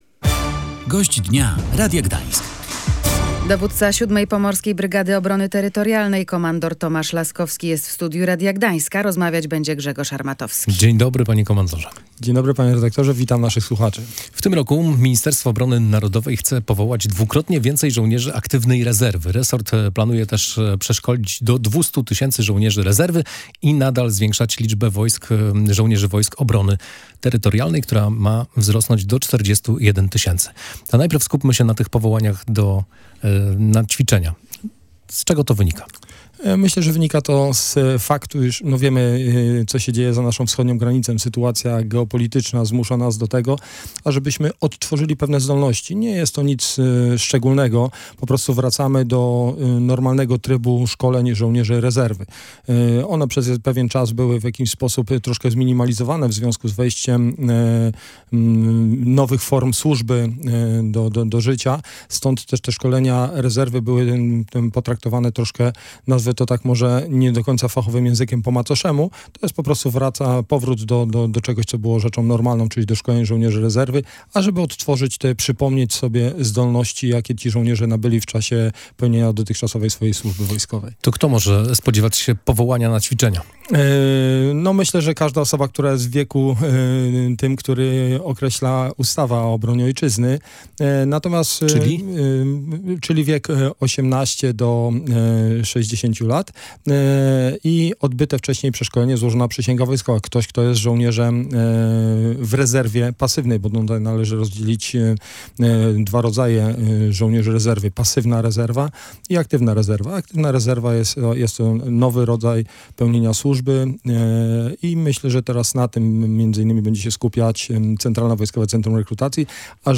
w audycji „Gość Dnia Radia Gdańsk”